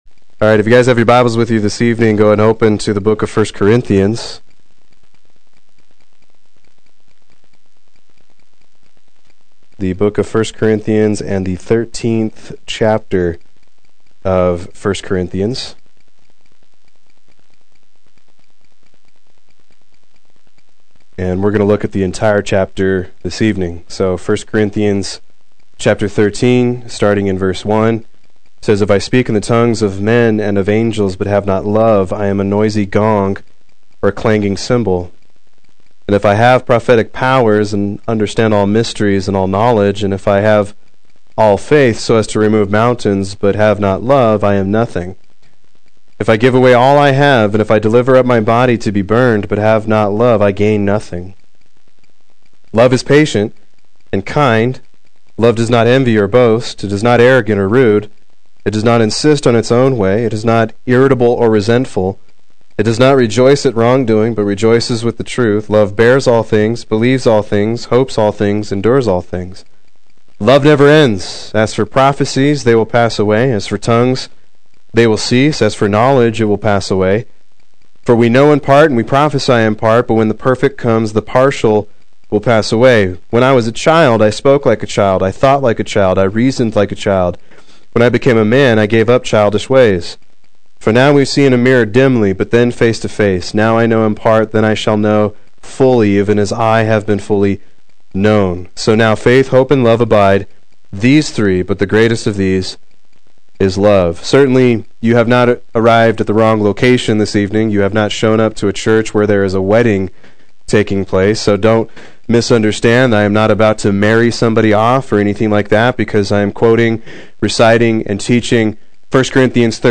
Proclaim Youth Ministry - 09/16/16
Play Sermon Get HCF Teaching Automatically.